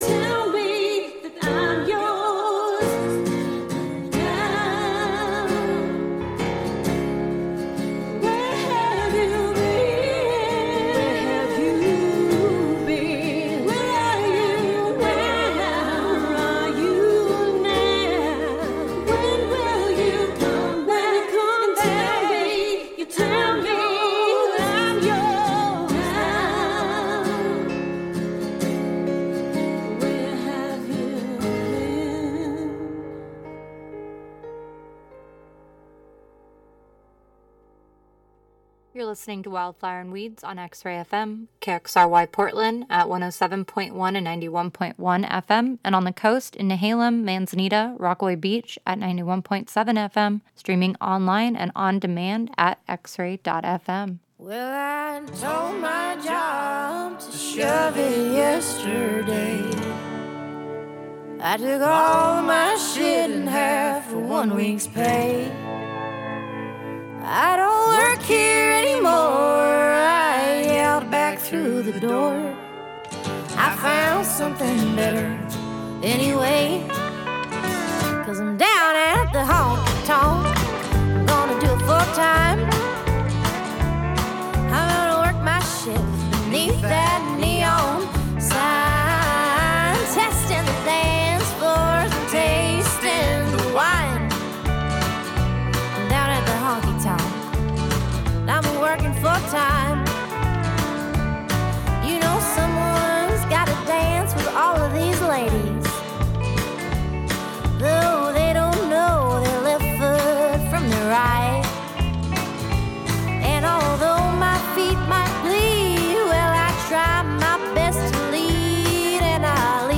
A mix of independent & vintage alt, outlaw, cosmic, gothic, red dirt & honky tonk country, roots, blue grass, folk, americana, rock and roll & even desert psych and stadium hits (when acceptable). We feature a strong emphasis on female artists. We tie together new independent artists and new releases with classics that inspired.